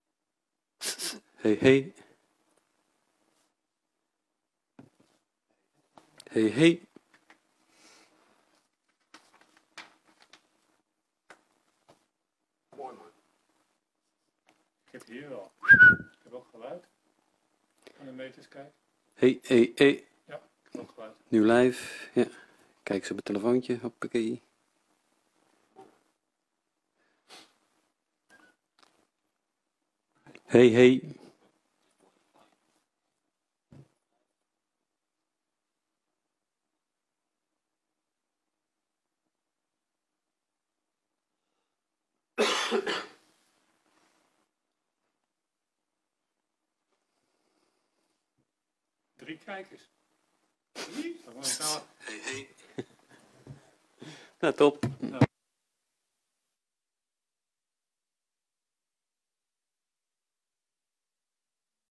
Gemeenteraad 29 augustus 2022 19:30:00, Gemeente Den Helder
Download de volledige audio van deze vergadering